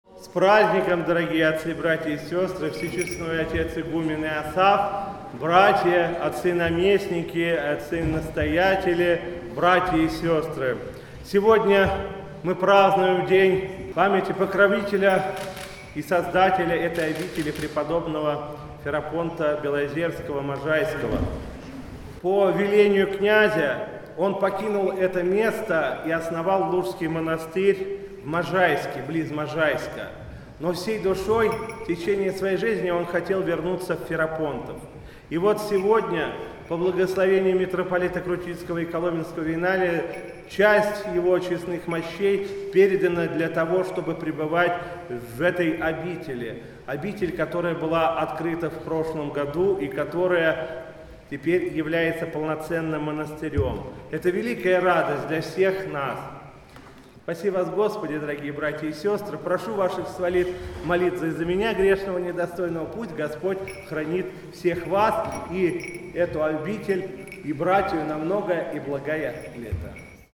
По окончании богослужения митрополит Игнатий обратился к присутствующим с архипастырским словом.